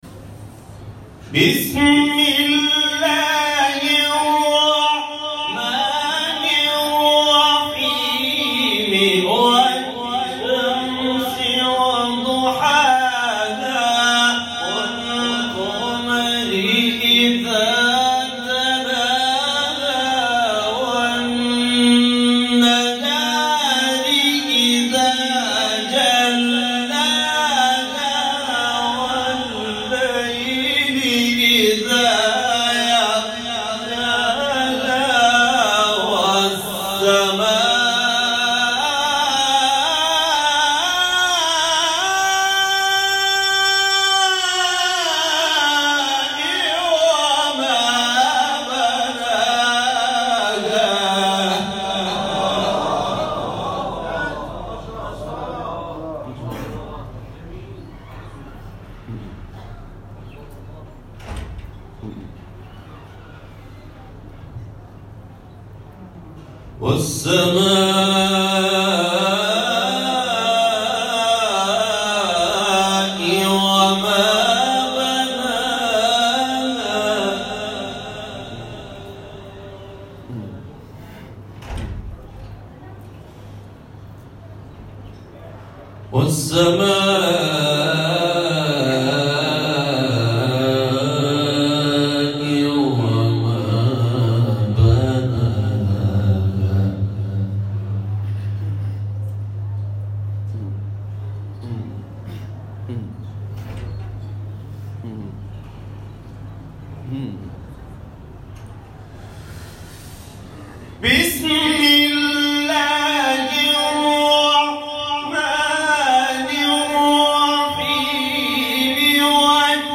گروه شبکه اجتماعی: مقاطعی از تلاوت قاریان بین‌المللی و ممتاز کشور‌ را می‌شنوید.
سوره مبارکه شمس اجرا شده در مقام سه‌گاه